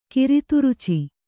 ♪ kiri turuci